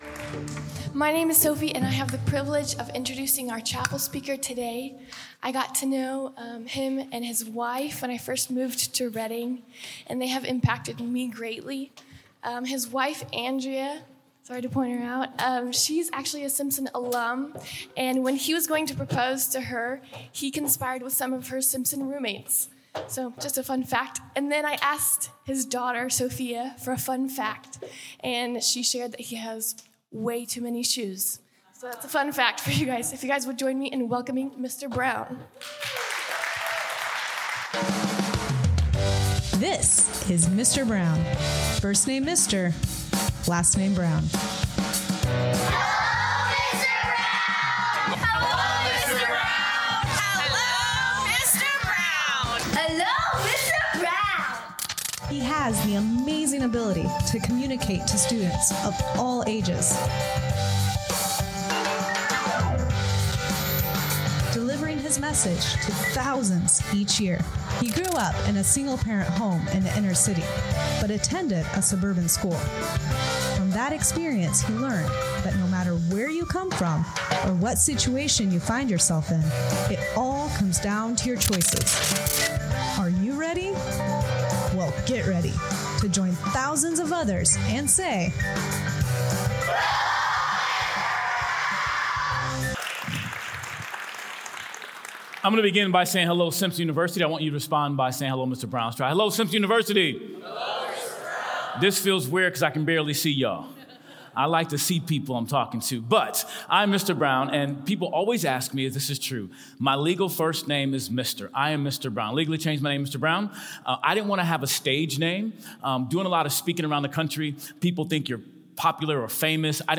This talk was given in chapel on Friday, November 15th, God Bless you.